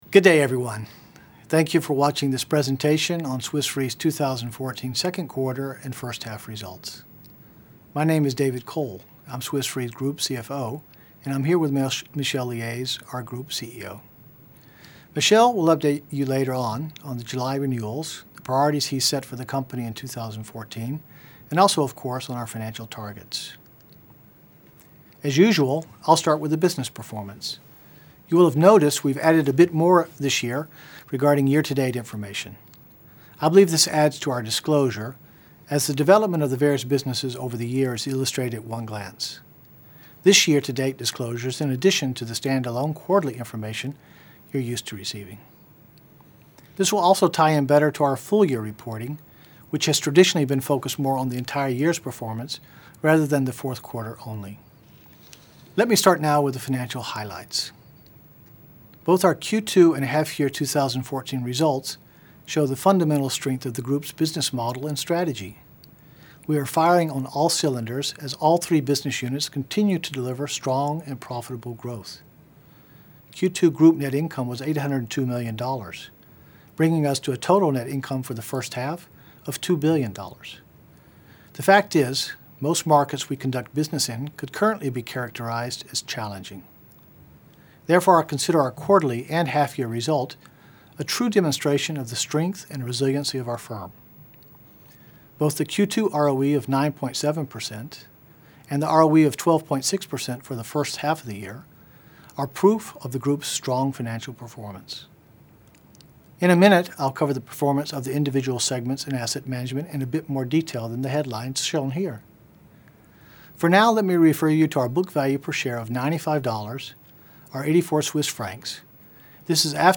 Audio of Q2 2014 results Video Presentation